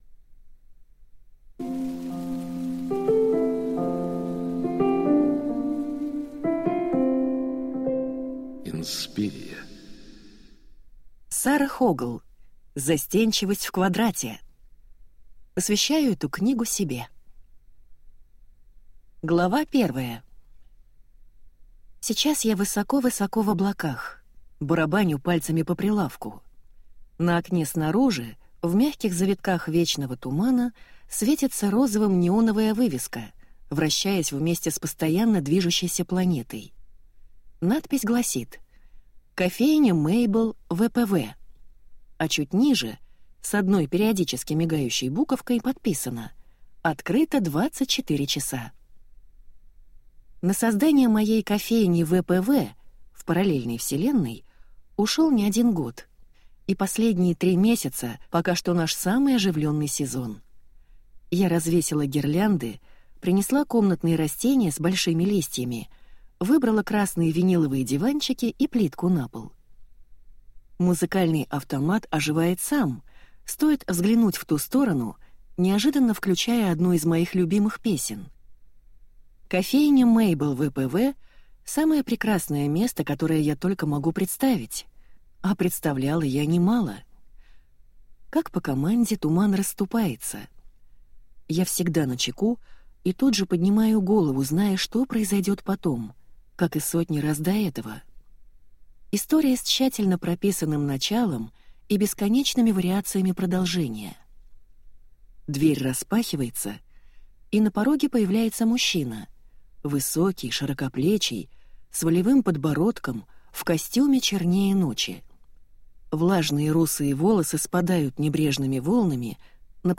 Аудиокнига Застенчивость в квадрате | Библиотека аудиокниг